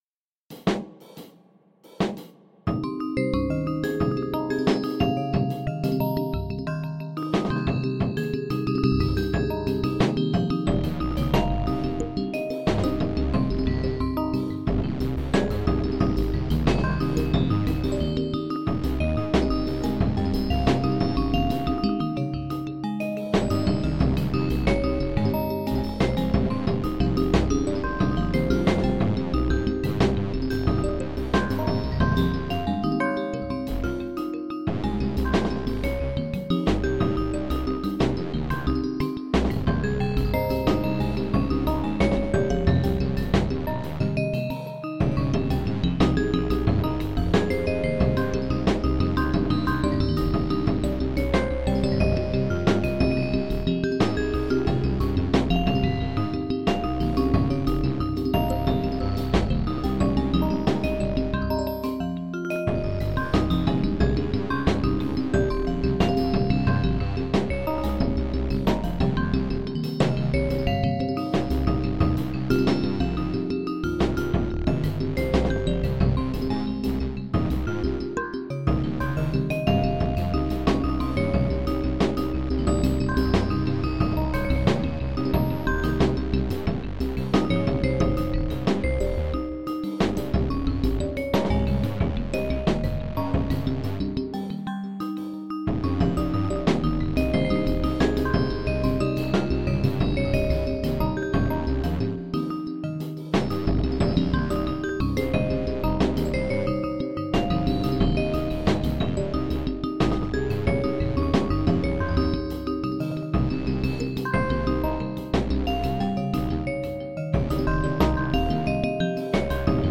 earlier experiments in generative music
The real versions come out differently each time they’re played, much as the repeating sections here aren’t really repeating.
• The various pitched instruments are randomly selecting notes (within the same scale).
• The drum part is one short loop, with many hits removed at random, and some of the remaining ones repeated as ghost notes.
• The lower bell sounds are using the same tricks as the drums, with different settings (and a sync’d delay to fill in gaps and give them some groove). They’re inspired by javanese gamelan
• The brighter bell sounds ring longer and play less often, as a melodic element to tie things together